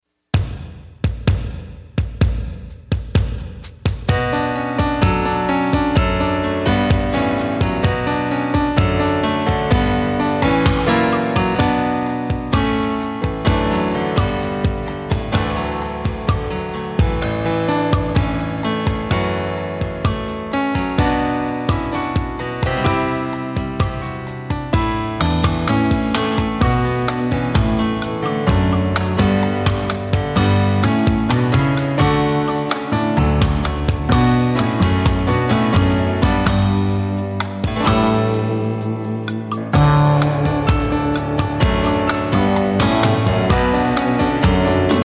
Listen to a sample of the instrumental..